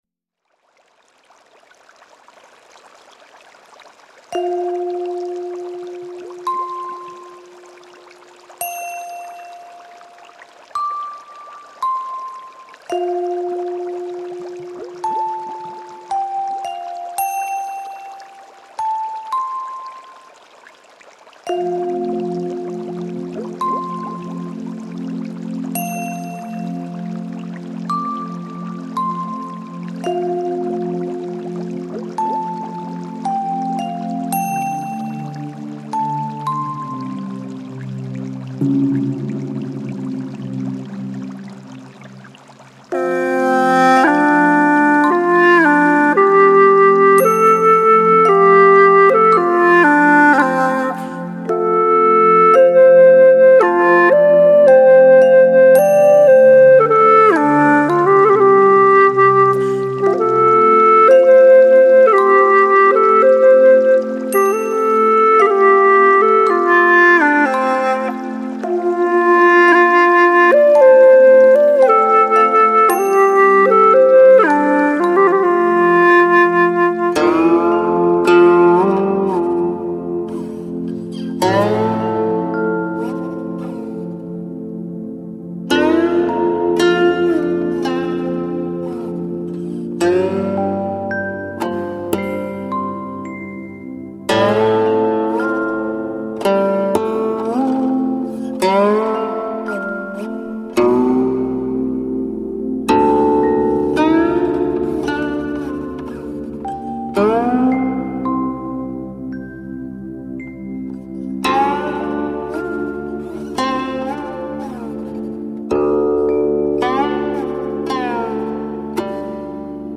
古琴演奏